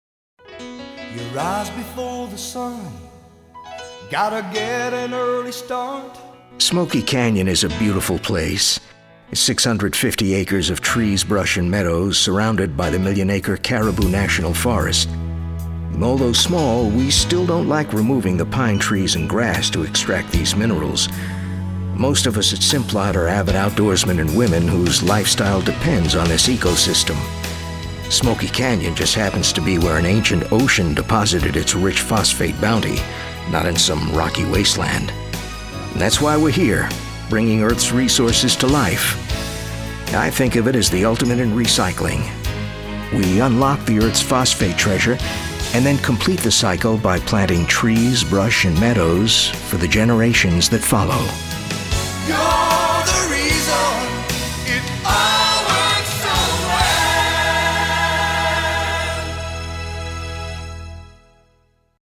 1 minute radio spot